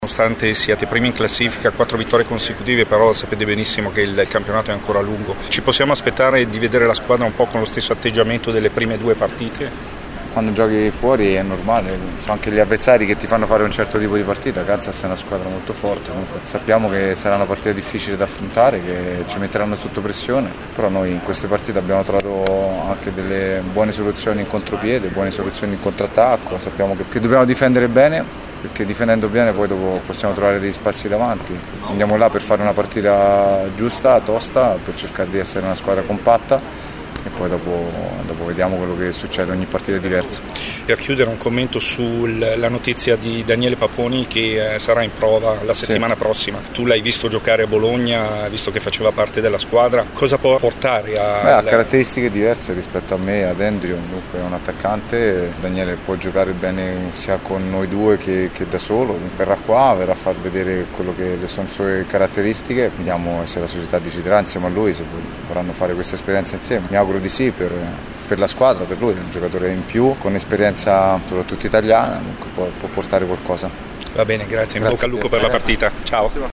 Le interviste dopo la rifinitura di questa mattina prima della partenza per il Kansas: